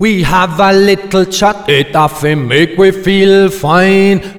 OLDRAGGA2 -L.wav